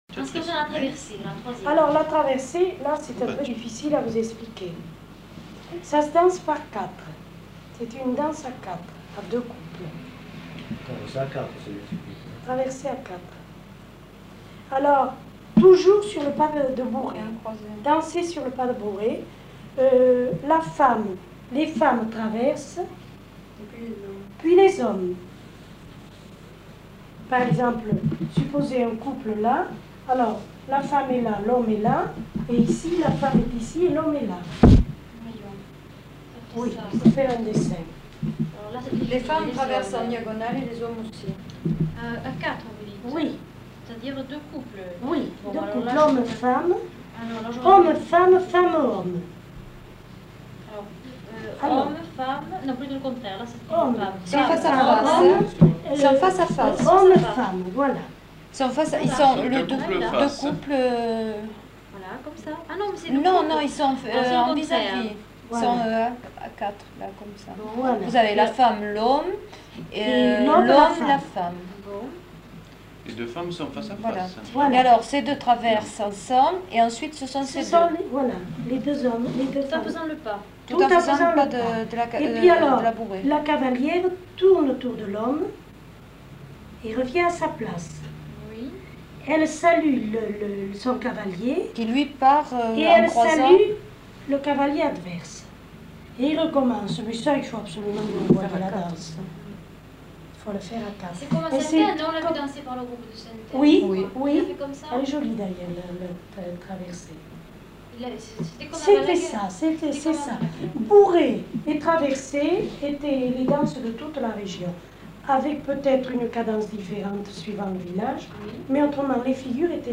Description de la bourrée (avec air fredonné)